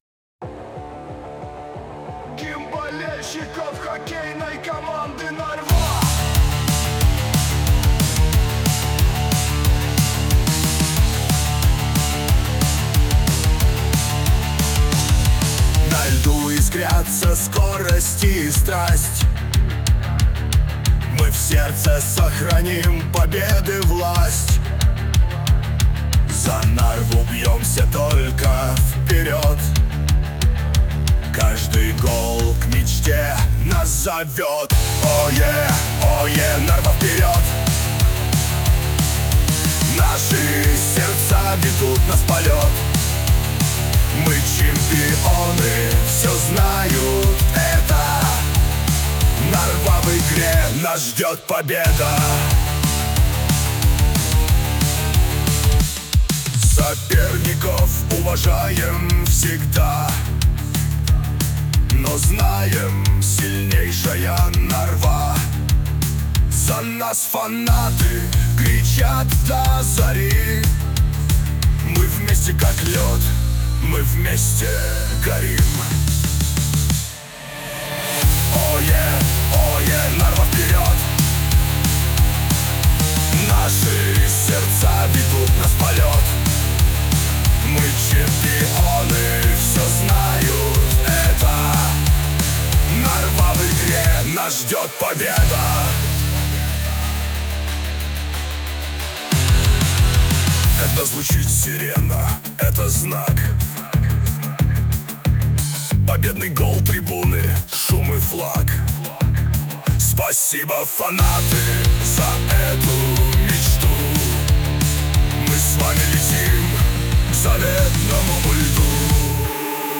RUS, Rock, Trance | 30.03.2025 07:51